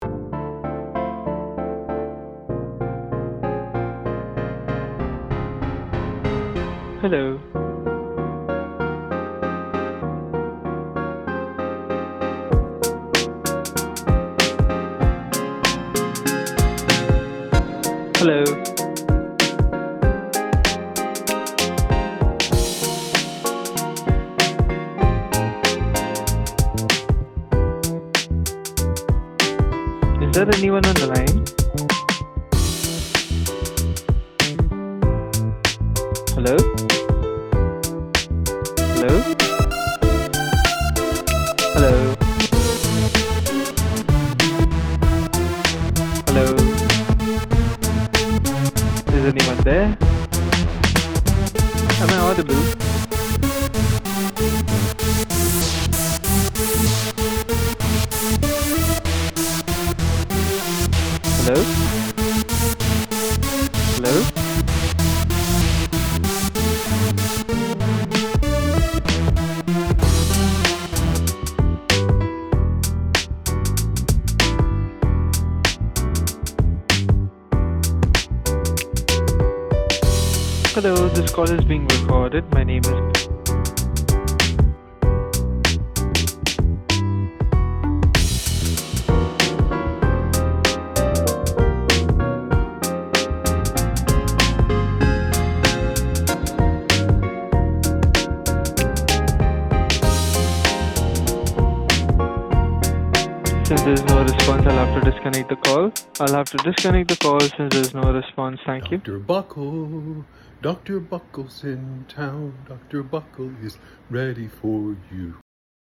drums, piano, bass, synthesizer